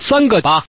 Man_tuple8.mp3